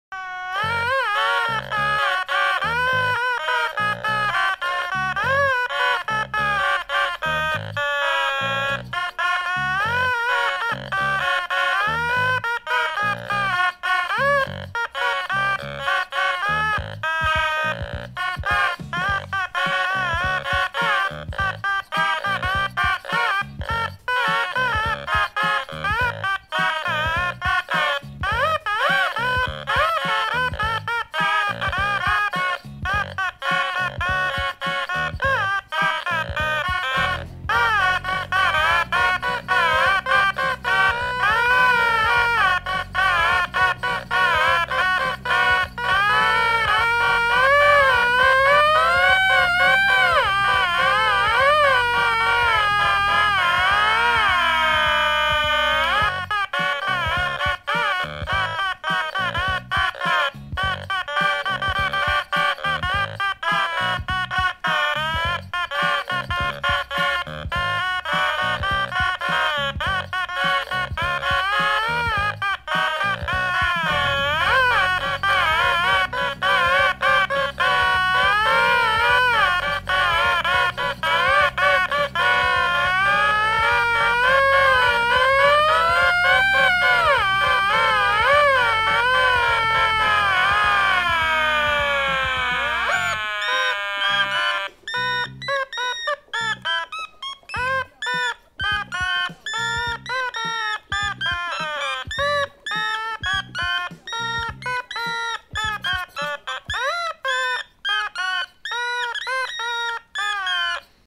find an otamatone cover